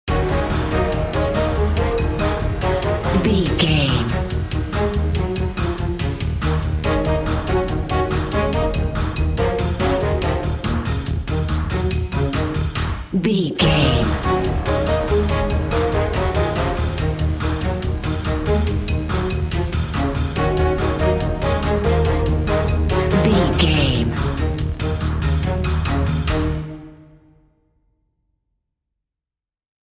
Aeolian/Minor
E♭
driving
intense
drum machine
bass guitar
orchestra
percussion